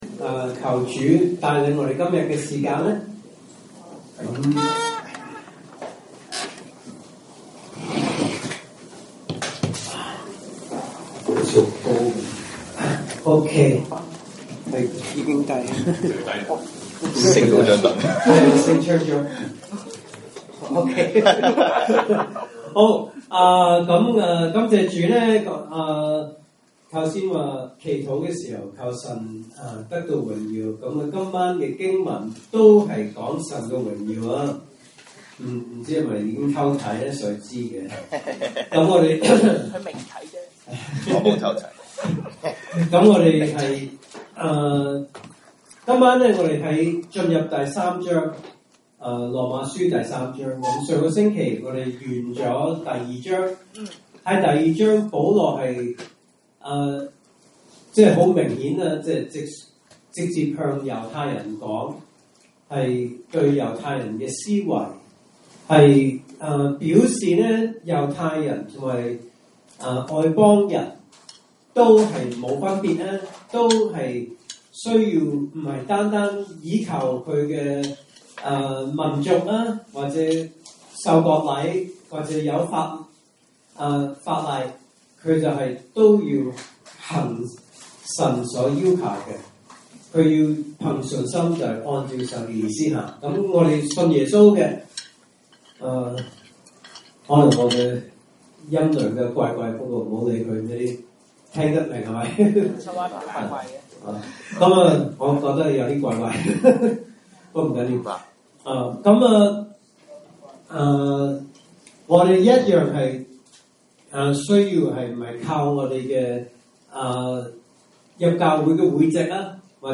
來自講道系列 "查經班：羅馬書"